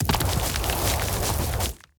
Free Fantasy SFX Pack
Rock Meteor Swarm 1.ogg